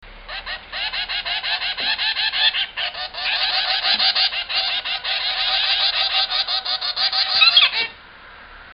Ha un bel canto sonoro e vivace che ama esprimere spesso e volentieri!
Il canto di Ivan